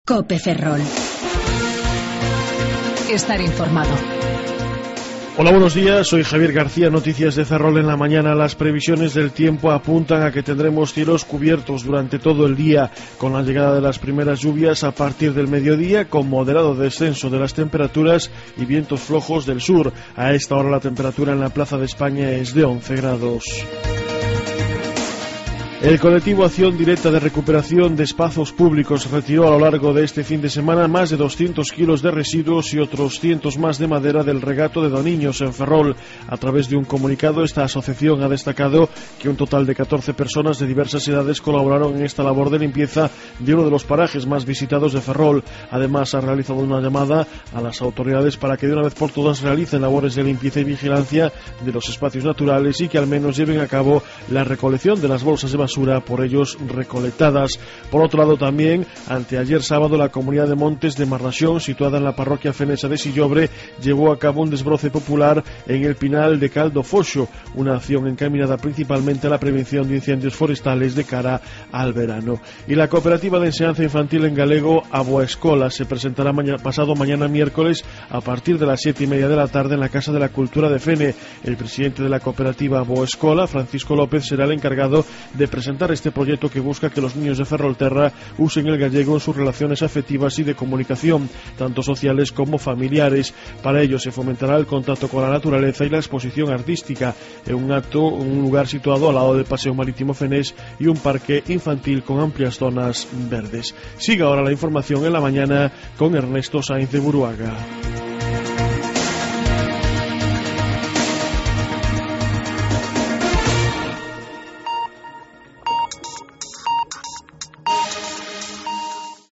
07:58 Informativo La Mañana